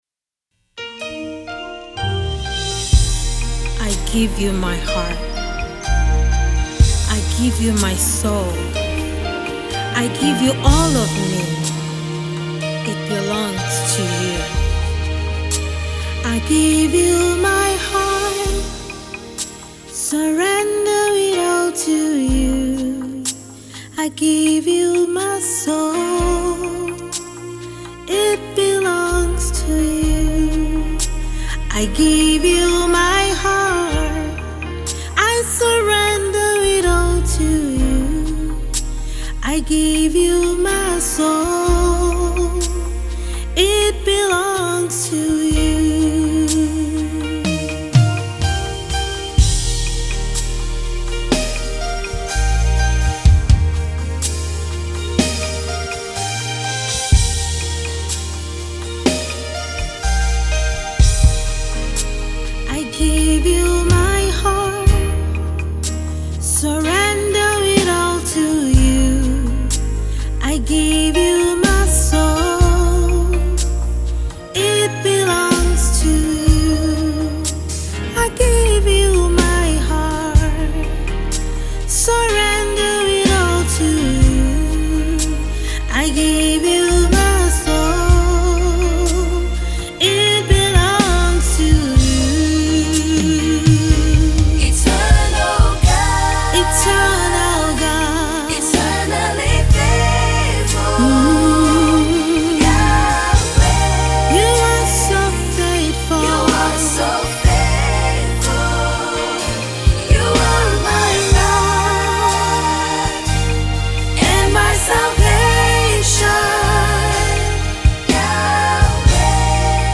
US-based Nigerian Gospel Singer-songwriter